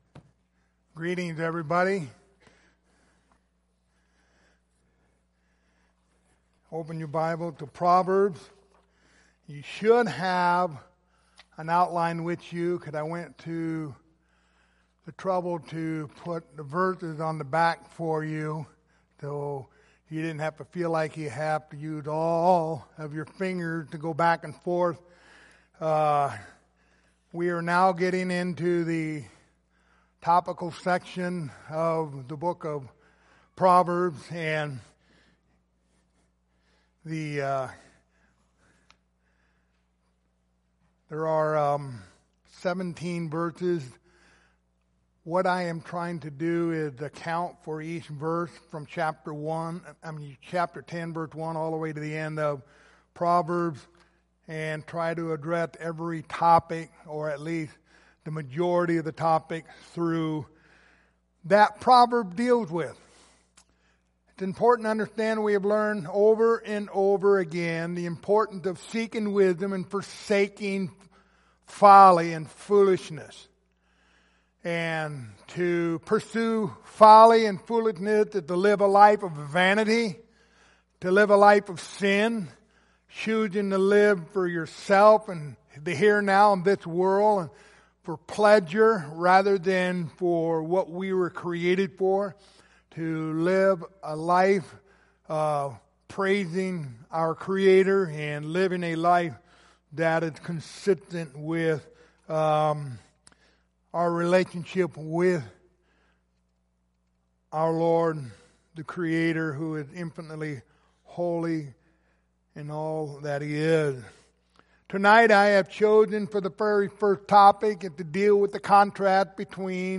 Passage: Proverbs 11:2 Service Type: Sunday Evening